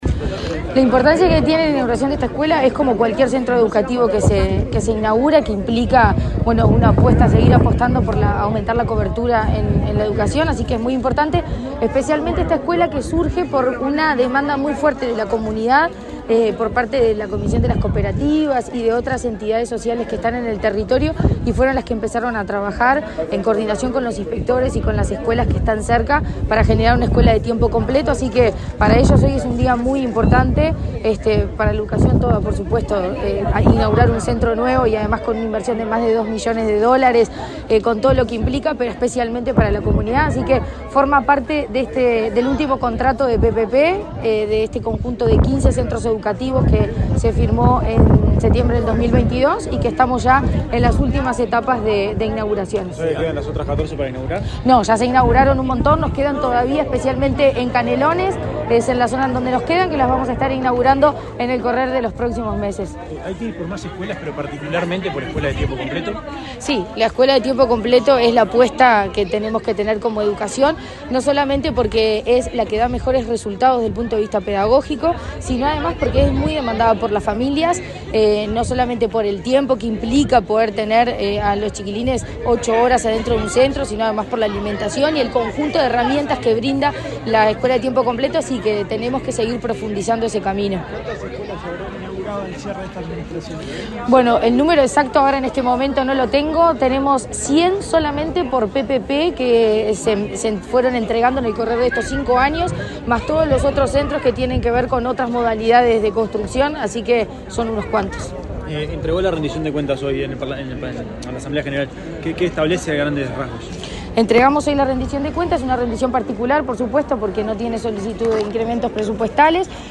Declaraciones de la presidenta de ANEP, Virginia Cáceres
Este viernes 28 la presidenta de la ANEP, Virginia Cáceres, dialogó con la prensa, luego de participar de la inauguración de la escuela de tiempo